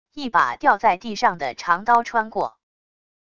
一把掉在地上的长刀穿过wav音频